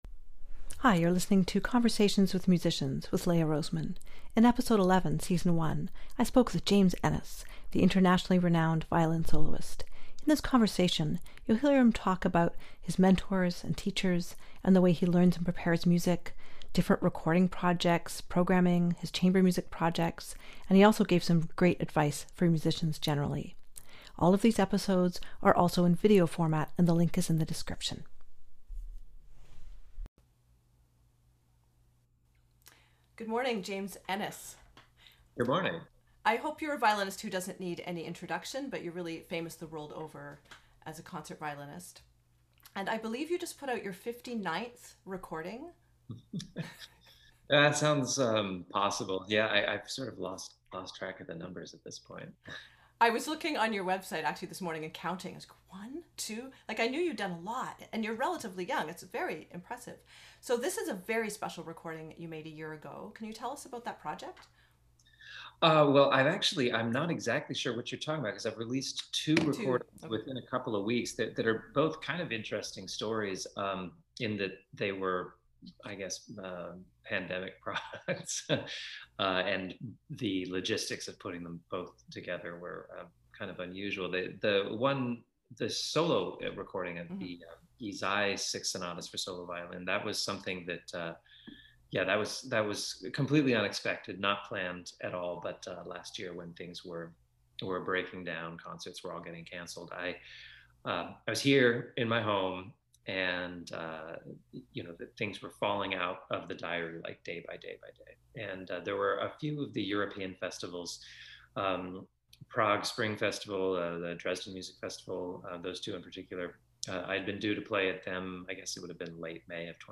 I was honoured to speak at length with James Ehnes about his career, collaborations, current projects, and his approach with practice and preparation for concerts.